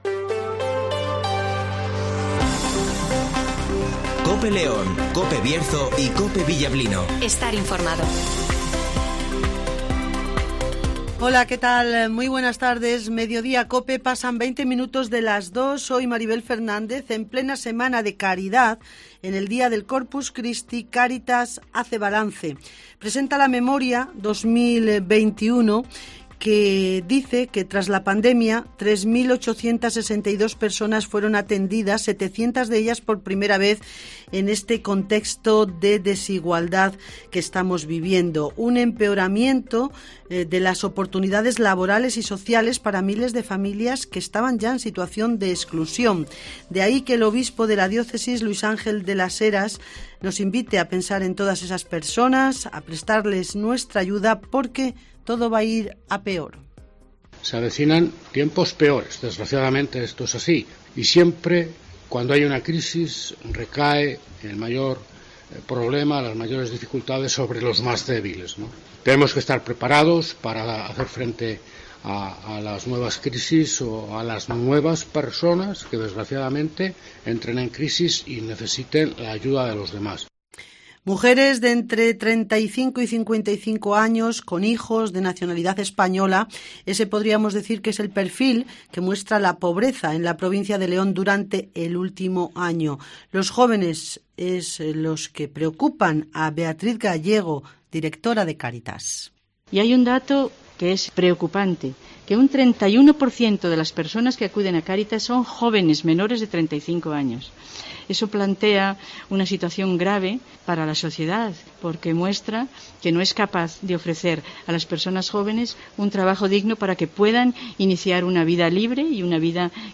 - D.M. Lúís Ángel de las Heras ( Obispo de la " Diócesis de León " )
- Luís Tudanca ( Secretario General del PSOE en León )